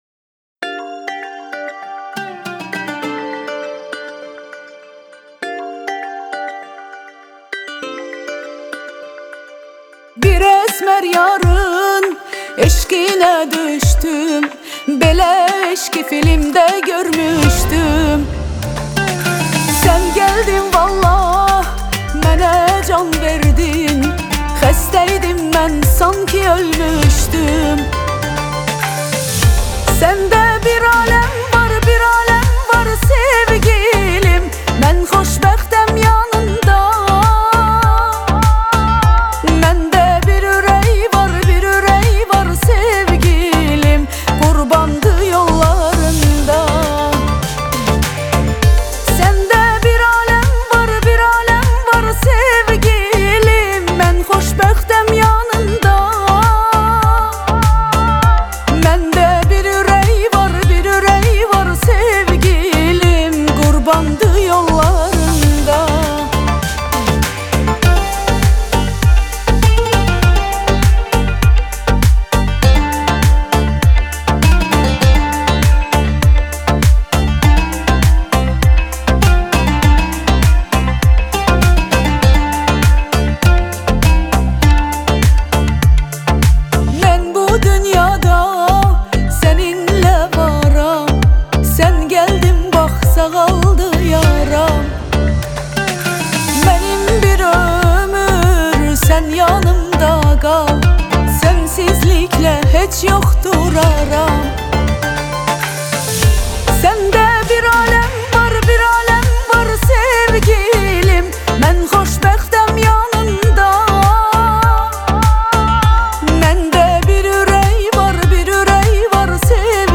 آهنگ آذربایجانی آهنگ شاد آذربایجانی آهنگ هیت آذربایجانی